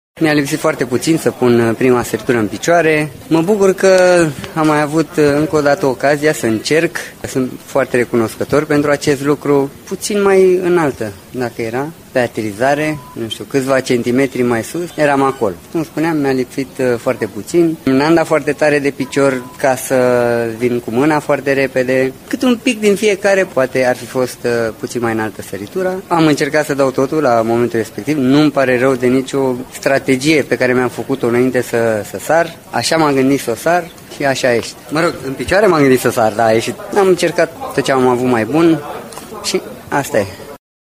Drăgulescu, care va împlini 41 de ani în decembrie, a avut o medie de 13.999. Sportivul nostru spune că diferența între reușita mult visată și ratarea accesului în finala probei favorite s-a făcut la câțiva centimetri: